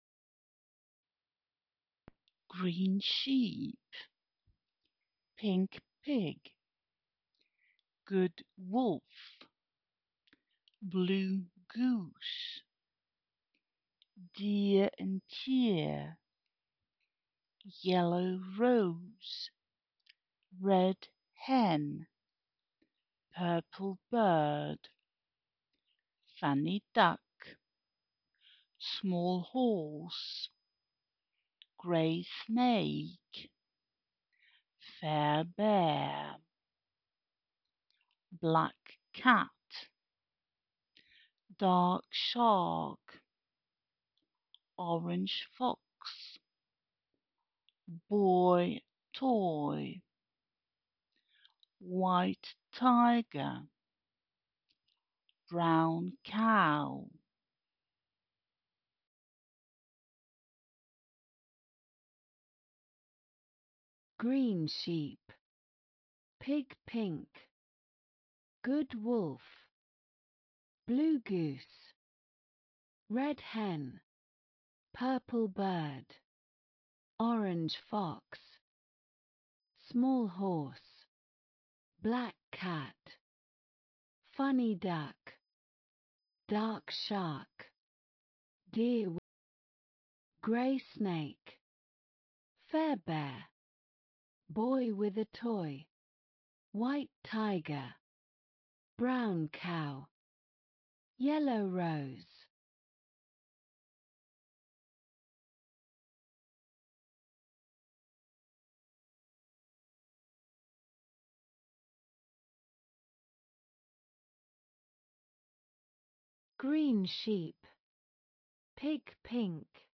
lesson_10_6_locuteurs.wav